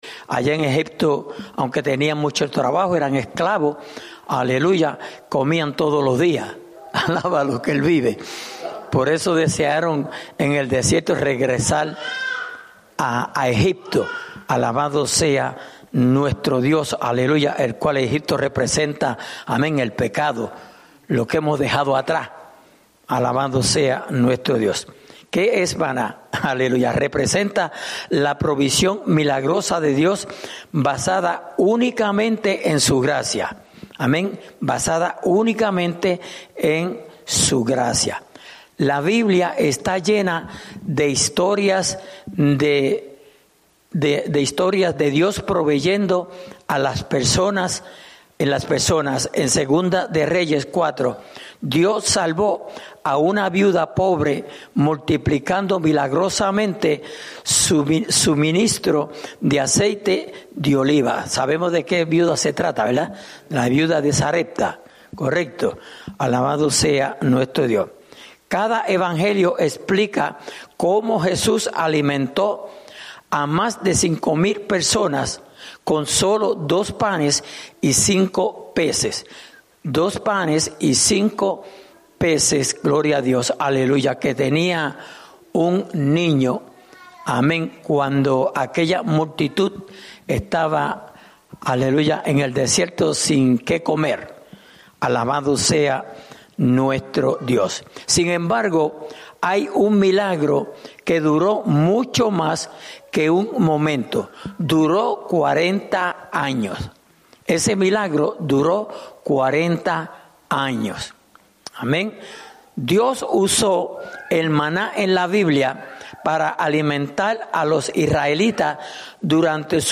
Estudio Bíblico: La Cena del Señor (Parte 2)